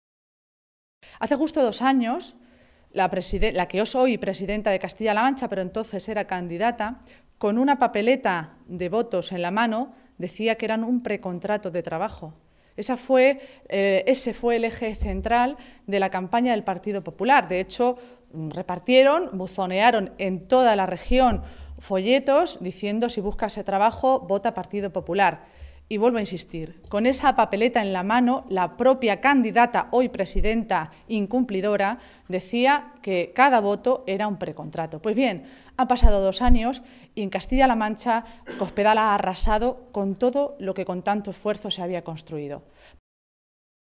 Maestre se pronunciaba de esta manera en una comparecencia ante los medios de comunicación, en mitad de la celebración del Comité Regional del PSOE castellano-manchego, que coincidía con un mitin organizado al mismo tiempo por el PP en Toledo con motivo del segundo aniversario de la llegada al poder de Cospedal.
Cortes de audio de la rueda de prensa